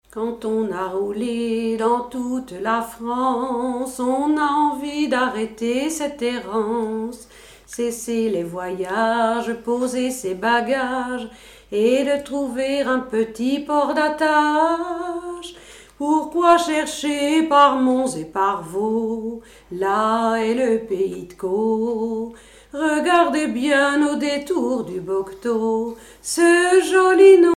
Chansons et commentaires
Pièce musicale inédite